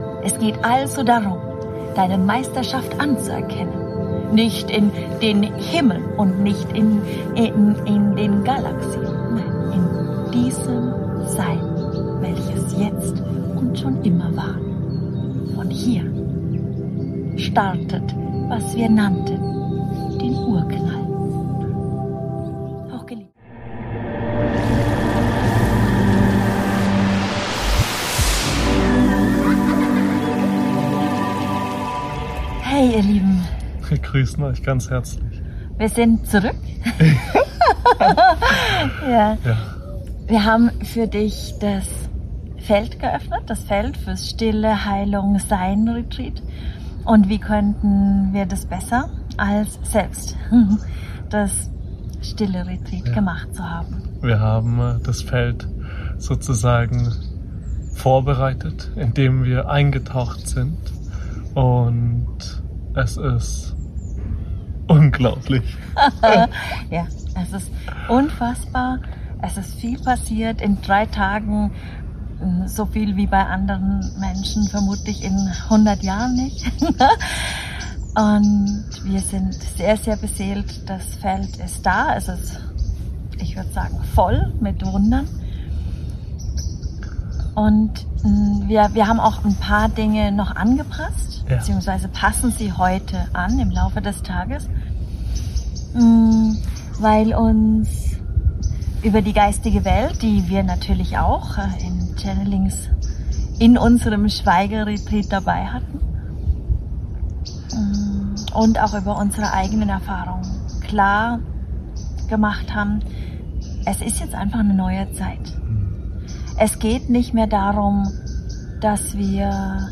Heute laden wir dich ein, in den hohen Frequenzen zu baden, mit denen wir das Feld und unsere Worte aufgeladen haben. Am Ende gibt es Worte und Klänge aus dem göttlichen Feld der Wunder.